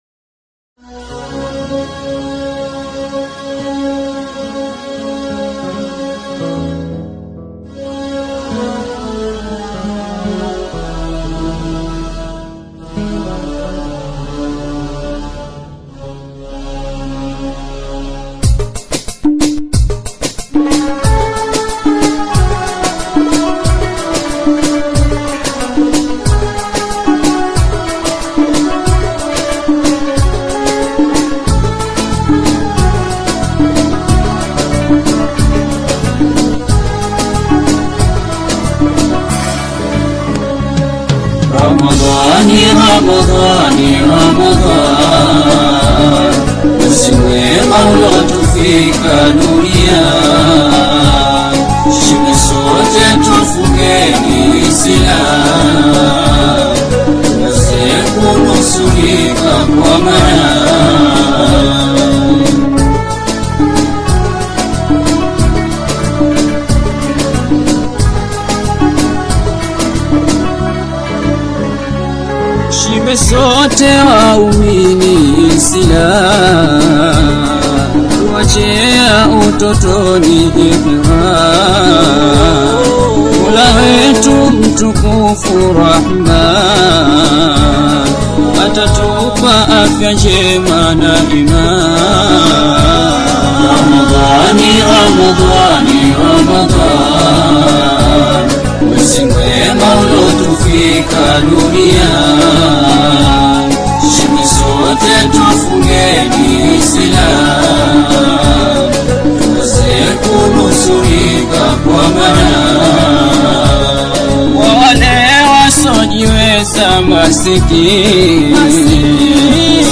QASWIDA